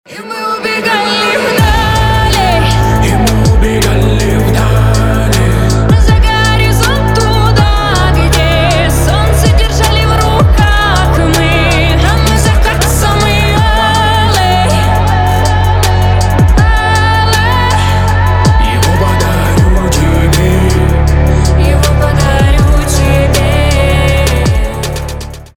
• Качество: 320, Stereo
лирика
дуэт
Trap
романтичные